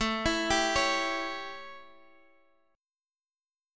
A7 Chord
Listen to A7 strummed